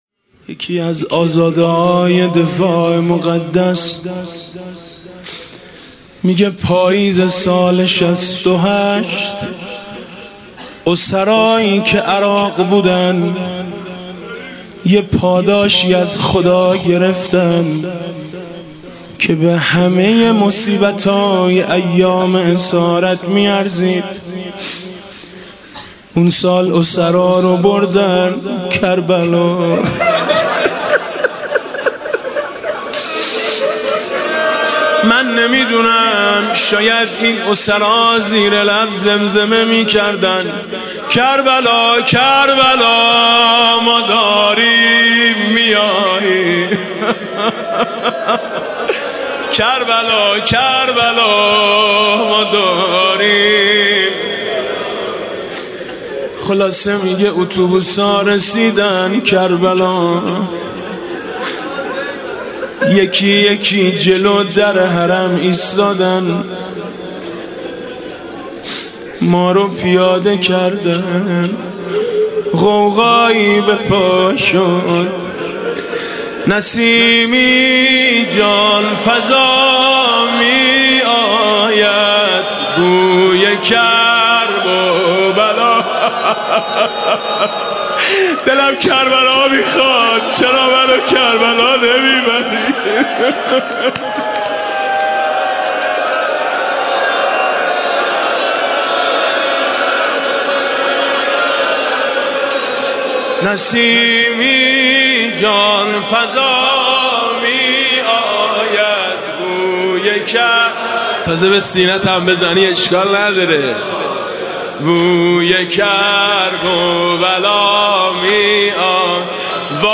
شب دوم محرم 96 - هیئت میثاق - مقتل - ورود کاروان امام حسین (ع) به کربلا